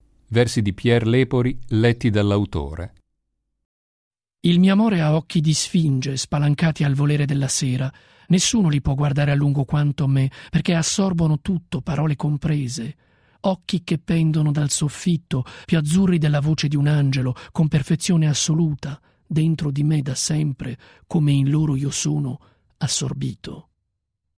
“Colpo di poesia” offre il microfono ai poeti della Svizzera italiana che interpretano direttamente le loro poesie.